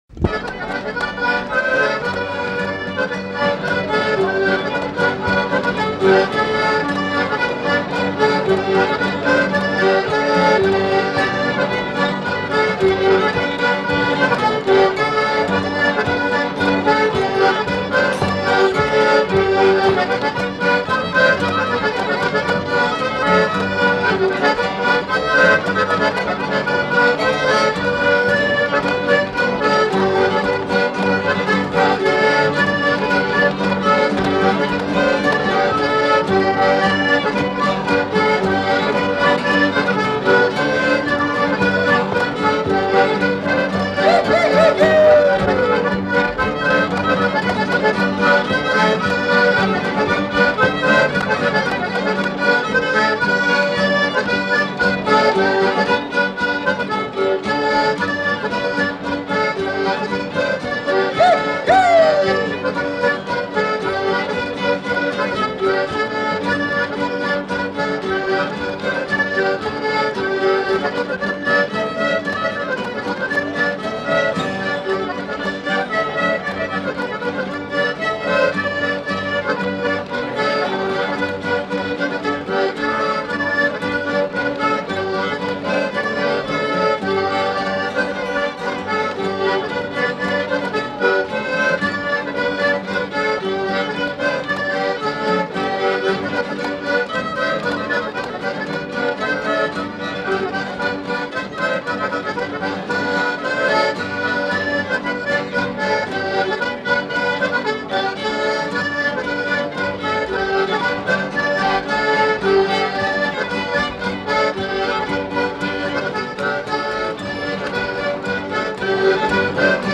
Rondeau
Orchestre du Conservatoire occitan (ensemble vocal et instrumental)
Aire culturelle : Savès
Genre : morceau instrumental
Instrument de musique : accordéon diatonique ; violon ; vielle à roue
Danse : rondeau